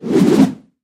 На этой странице собраны звуки бумеранга: от свиста в полете до мягкого приземления в руку.
Шум взмаха бумеранга